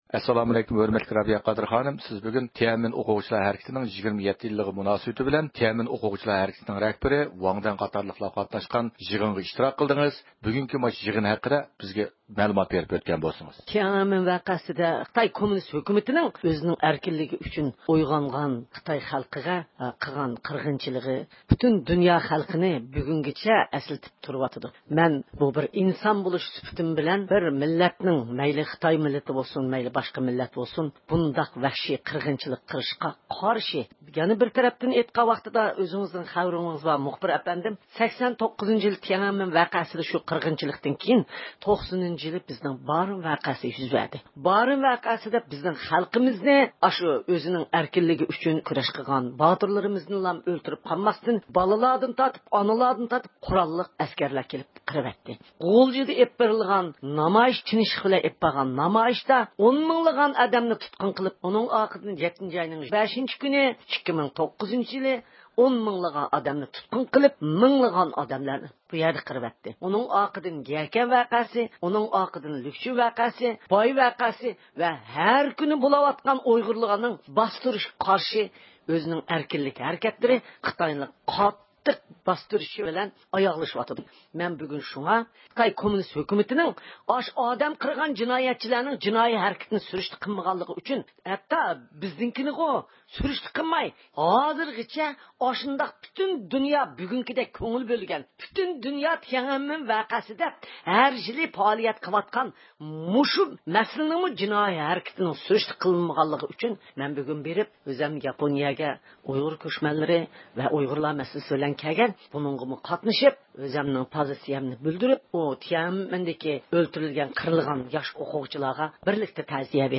بىز يىغىندىن كېيىن، بۇ ھەقتە تەپسىلىي مەلۇماتقا ئىگە بولۇش ئۈچۈن دۇنيا ئۇيغۇر قۇرۇلتىيىنىڭ رەئىسى رابىيە قادىر خانىم بىلەن سۆھبەت ئېلىپ باردۇق.